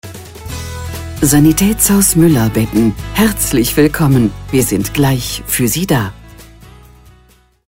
IVR Ansage Sanitätshaus Müller Betten
Mueller-Betten-Ansage-ivr.mp3